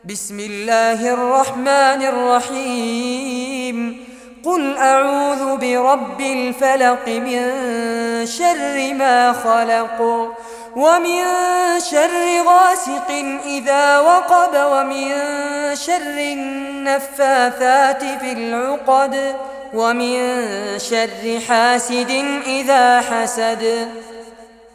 Surah Al-Falaq Recitation by Fares Abbad
Surah Al-Falaq, listen or play online mp3 tilawat / recitation in Arabic in the beautiful voice of Sheikh Fares Abbad.